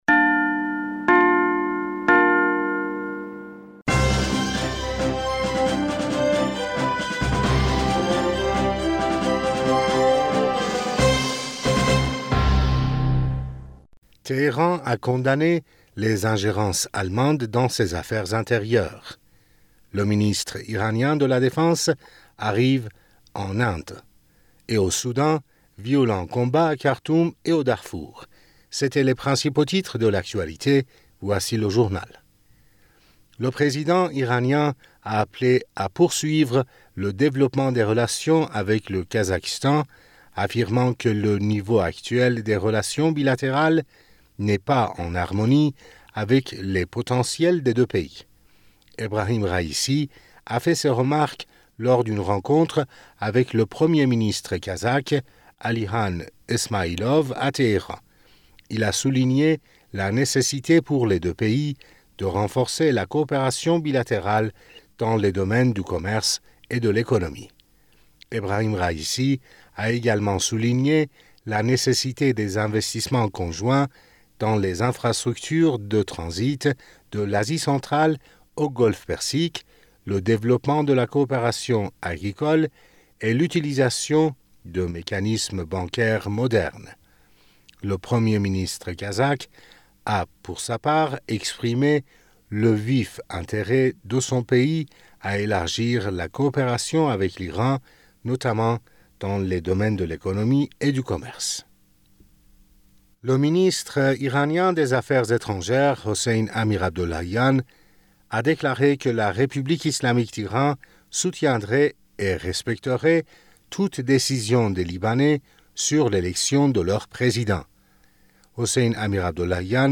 Bulletin d'information du 27 Avril 2023